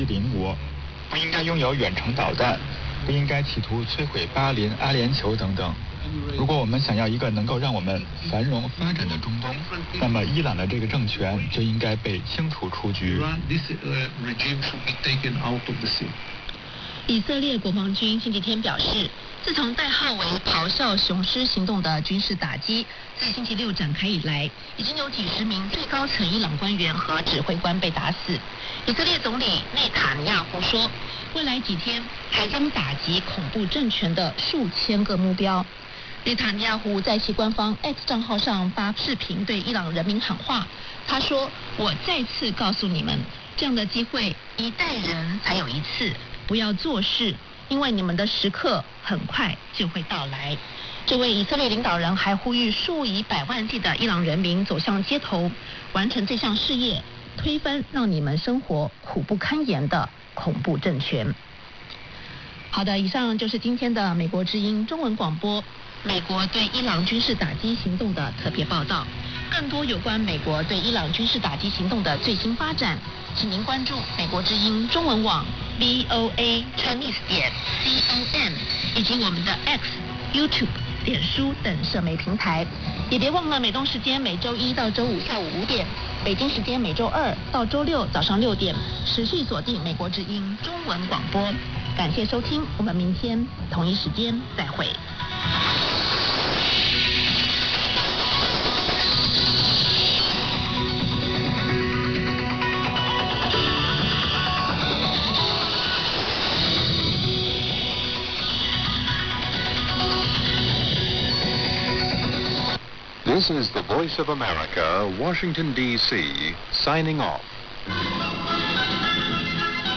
It was the familiar tune of Yankee Doodle being played by the Voice of America on 7500 kHz just prior to 2200 hours UTC.
Though none of the actual programs are in English, the ID at the beginning and end of the transmission are.
They were made using Kiwi SDR’s located in Europe.